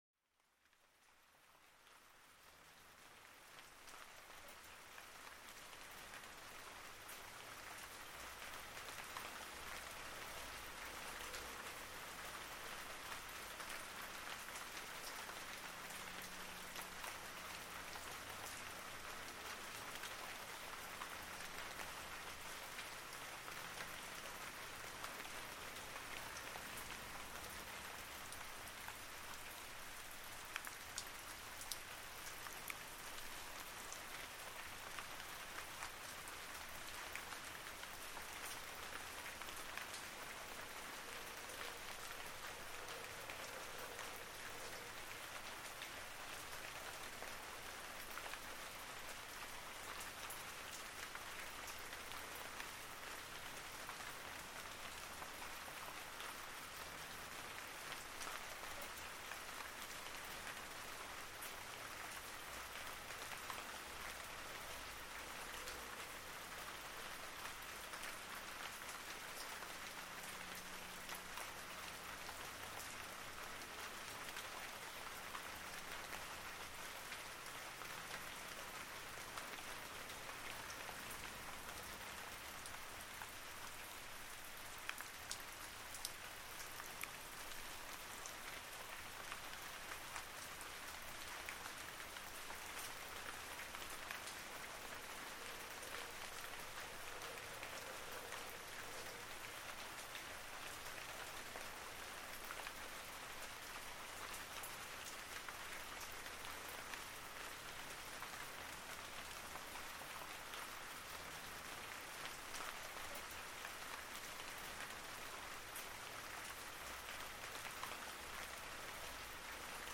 Pluie apaisante sur les feuilles pour une détente profonde
Plongez-vous dans le doux murmure de la pluie qui caresse les feuilles, un son enveloppant qui apaise l'esprit. Chaque goutte crée une symphonie naturelle, parfaite pour relâcher le stress accumulé.